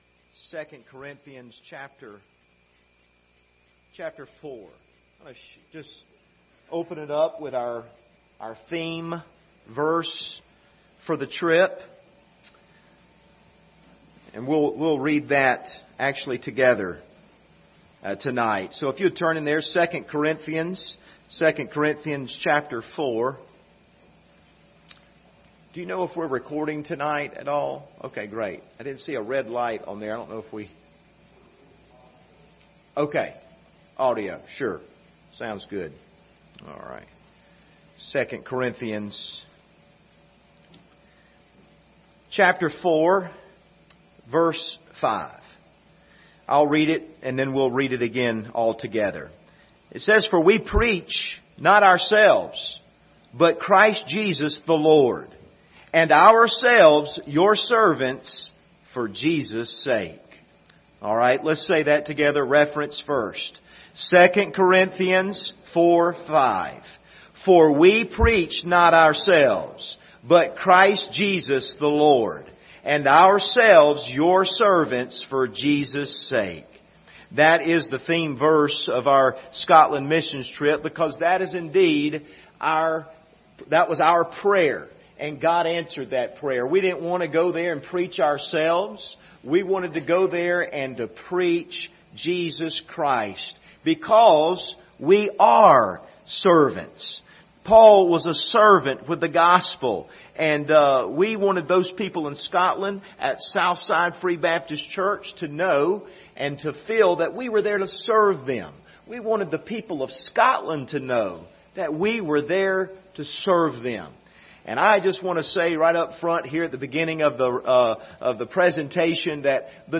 Scotland Missions Team Testimonies
Speaker: CCBC Members
Service Type: Sunday Evening